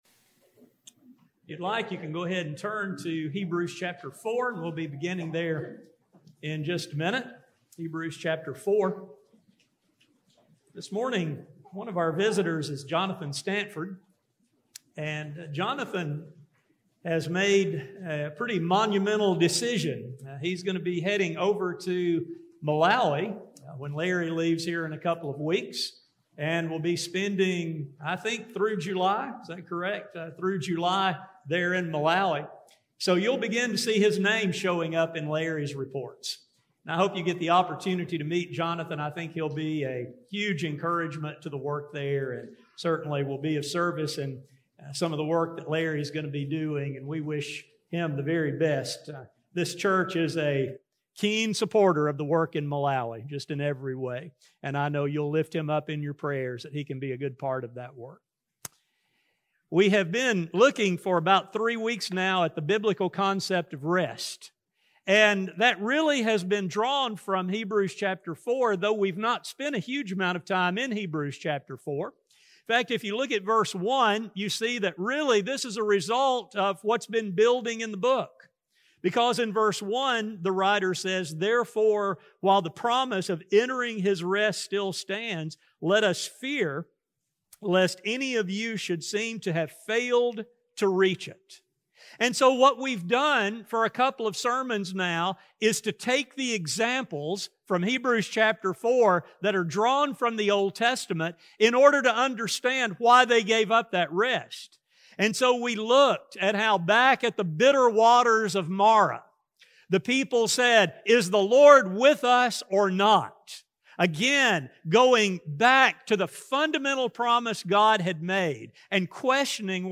This is the third and final sermon in a series entitled, “Rest, in Peace.”